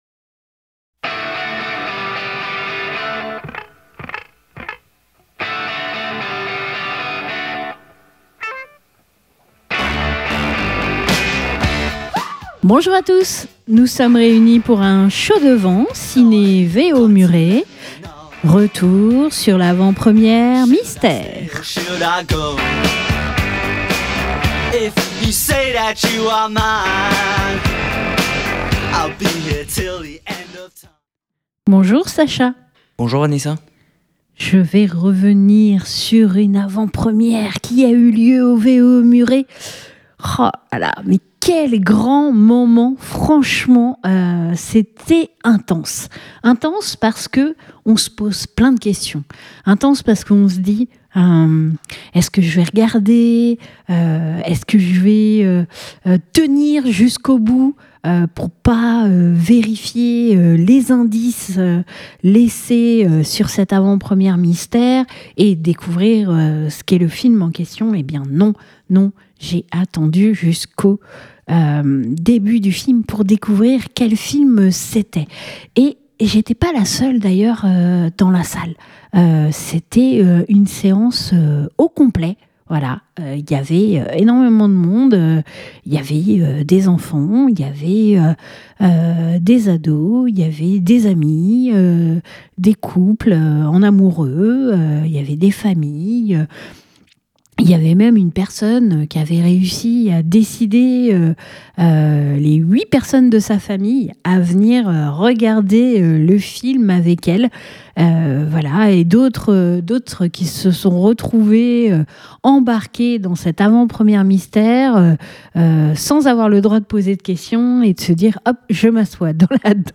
Avant le début de la projection nous sommes partis à la rencontre du public pour savoir ce qui les avait attiré dans ce concept.
Je vous propose d'écouter les avis du public sans vous apprendre le film et vous dérouler la liste de la tournée des avant-premières de ce film mystère.